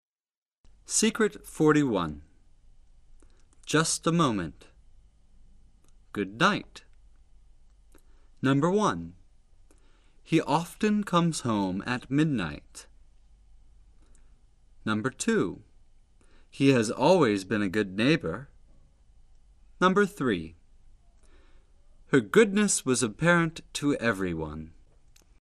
(2)爆破音 + 鼻辅音